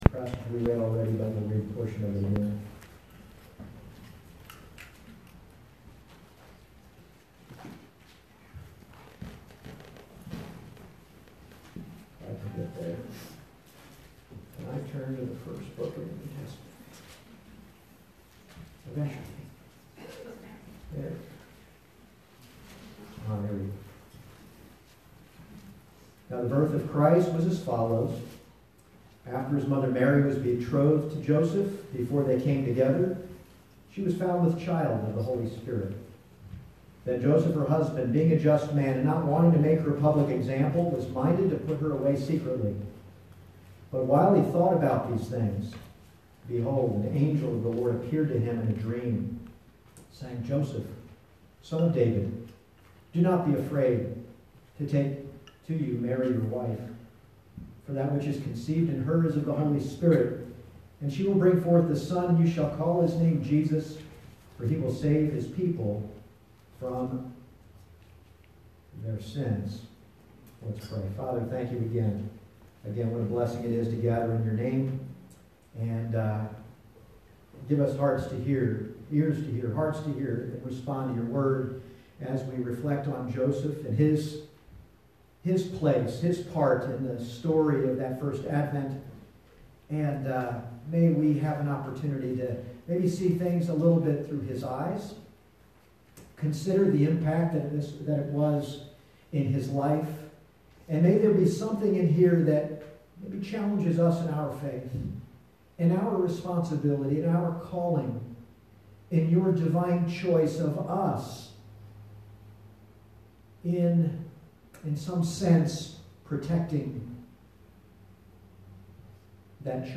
Passage: Matt 1:18-19 Service Type: Sunday Morning « Mary